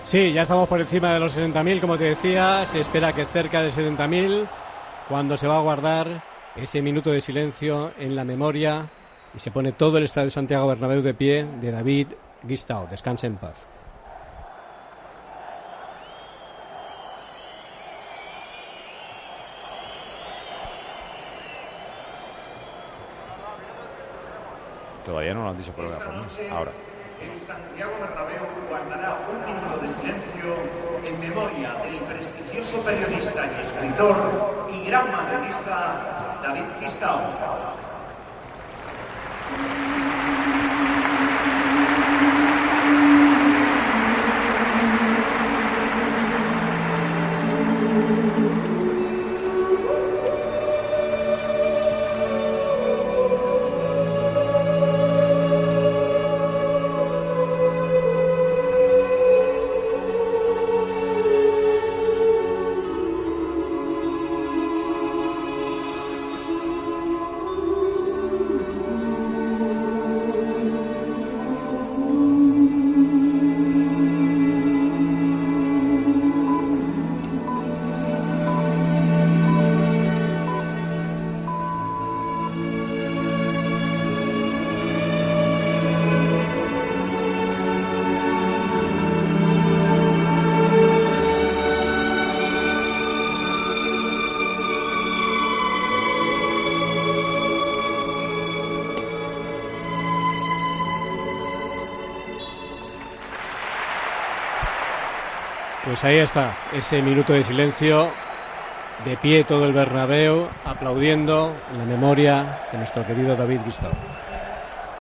El Santiago Bernabéu guardó un respetuoso minuto de silencio en memoria del periodista David Gistau
El periodista David Gistau, fallecido el pasado domingo, tuvo su recuerdo en la previa del Real Madrid - Celta.